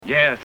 Yes (fed up)
Category: Movies   Right: Personal